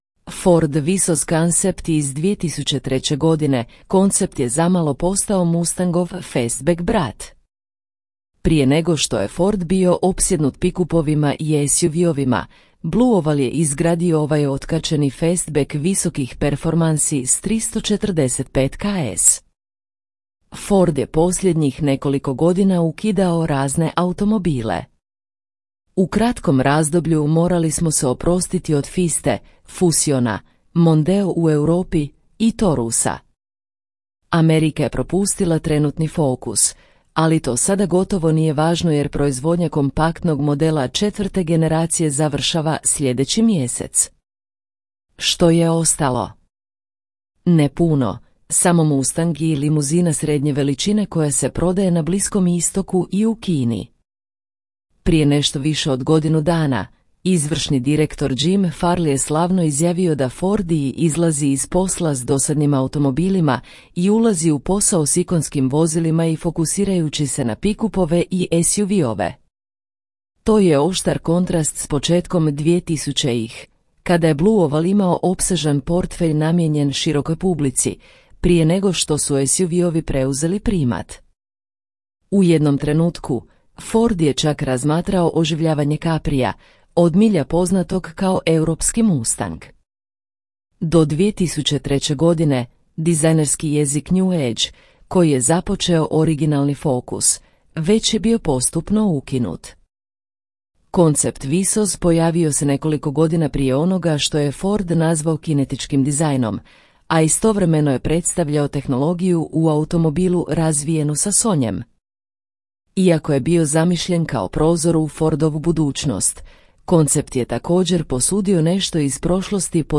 POSLUŠAJTE AUDIO SNIMKU OBJAVLJENOG TEKSTA U ČLANKU (vrijeme 8:21 min)